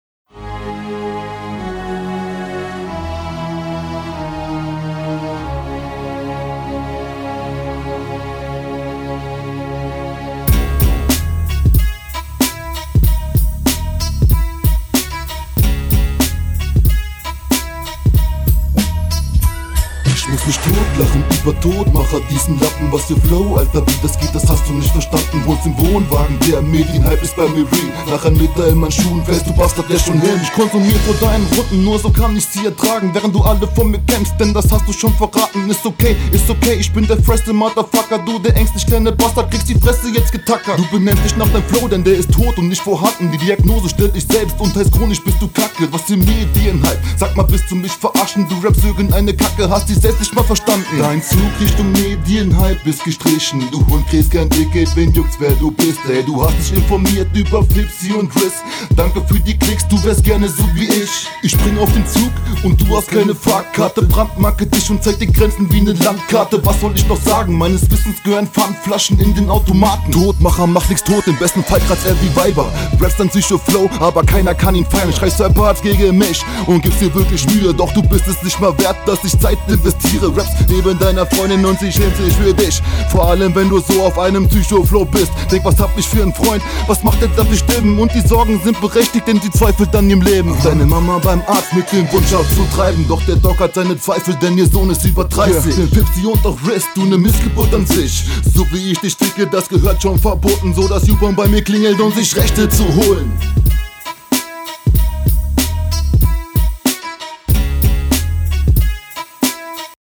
Flow: Klingt jetzt nicht bad, aber flasht auch nicht wirklich. Manche Betonungen bisschen weird.